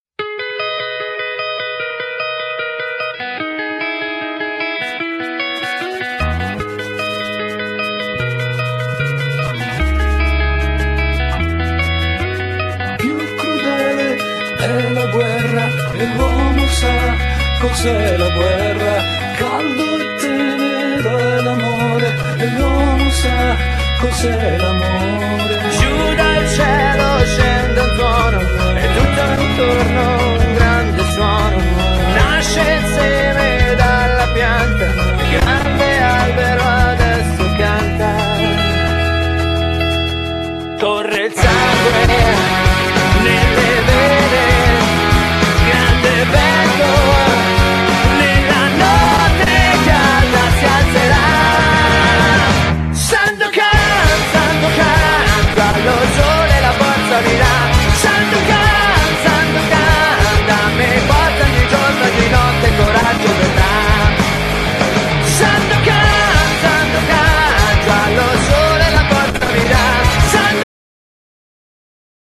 Genere : Ska Punk